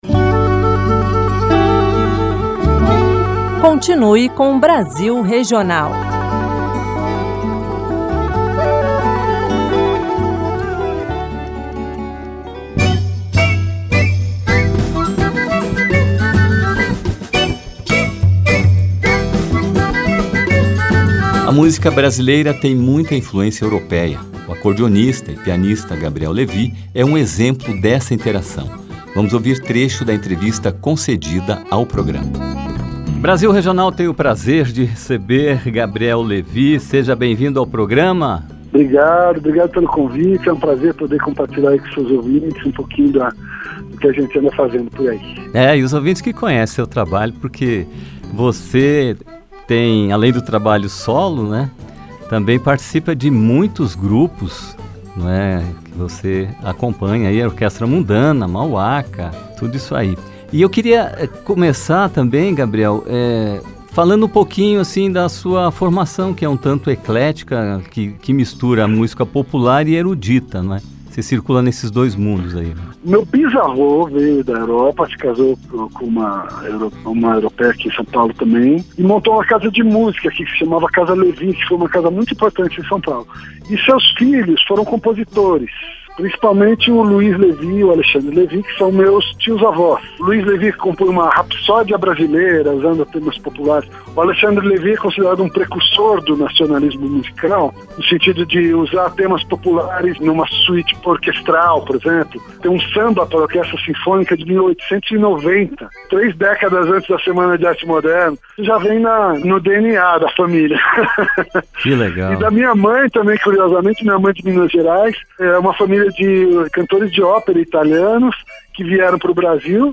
O programa especial, em comemoração aos 25 anos da Rádio Senado, faz uma retrospectiva lembrando músicas, entrevistas e mensagens veiculadas pelo Brasil Regional desde a primeira edição.